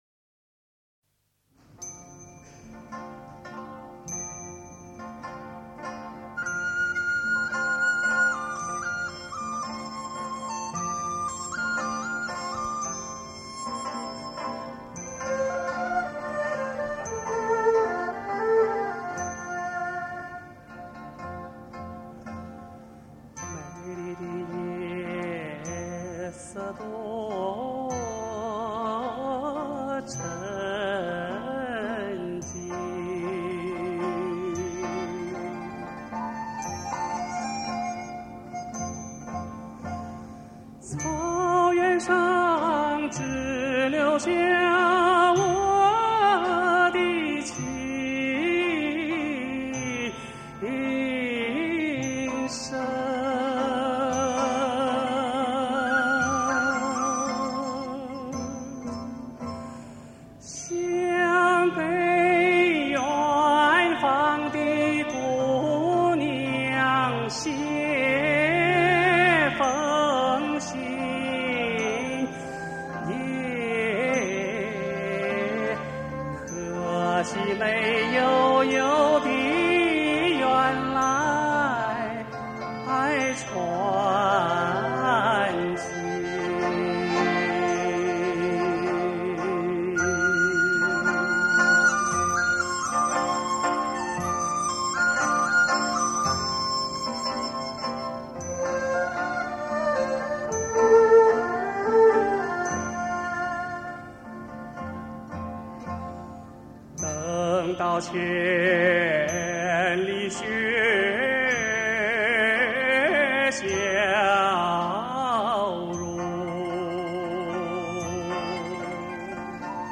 民族器乐伴奏 现场实况录音
传统民歌专辑